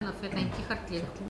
Localisation Saint-Jean-de-Monts
Langue Maraîchin
Catégorie Locution